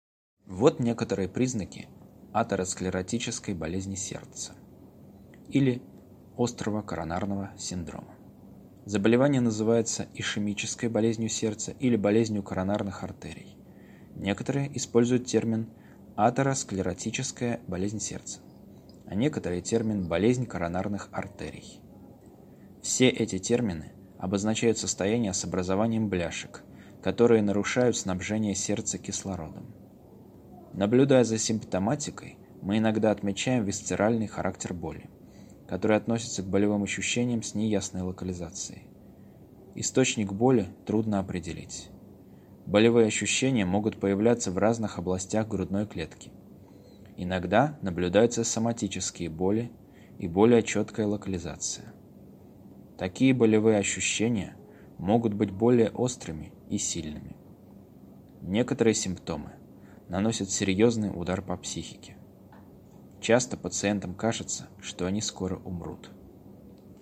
Перевод и озвучка на русском (медицина)